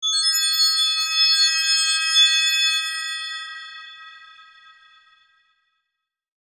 Download Fairy sound effect for free.
Fairy